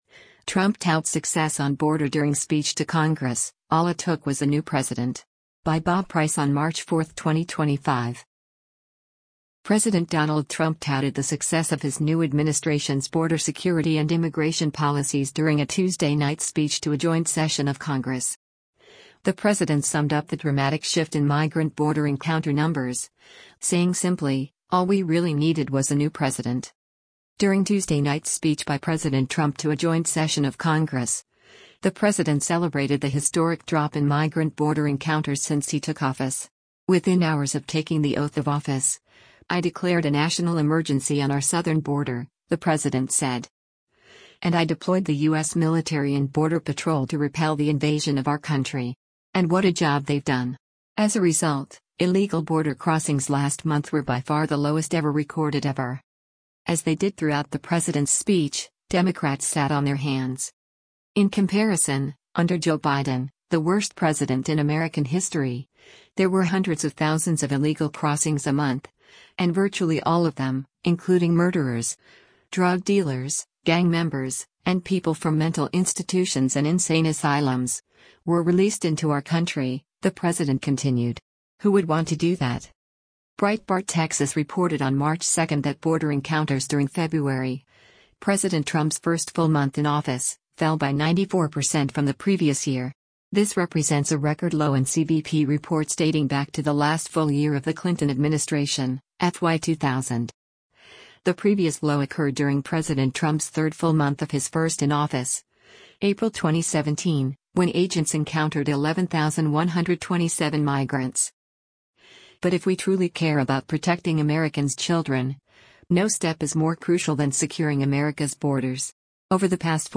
President Donald Trump touted the success of his new administration’s border security and immigration policies during a Tuesday-night speech to a joint session of Congress.
As they did throughout the president’s speech, Democrats sat on their hands.